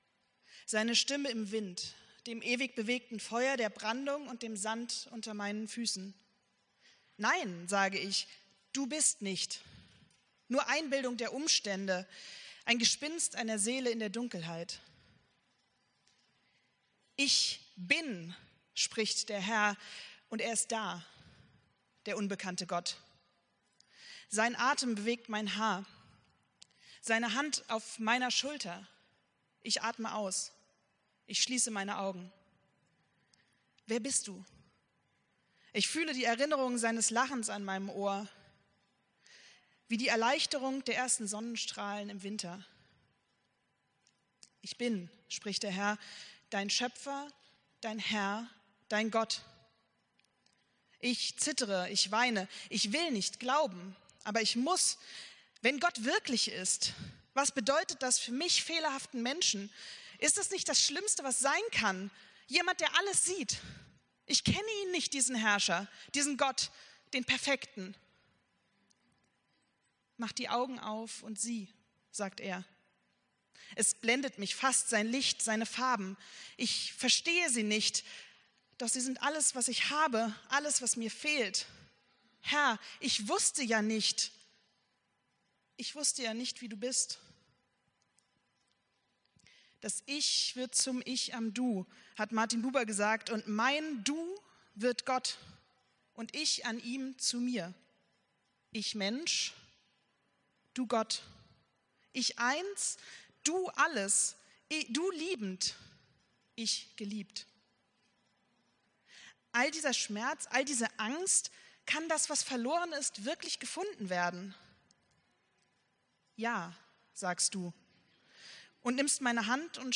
Predigt vom 22.06.2025